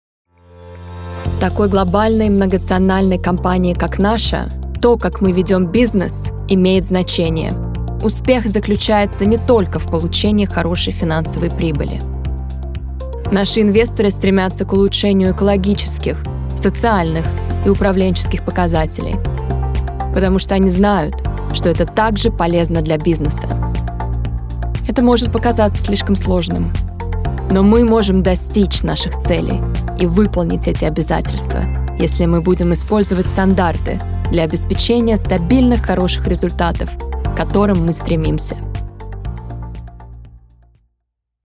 Eastern European, Russian, Female, 20s-40s